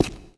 stone02.wav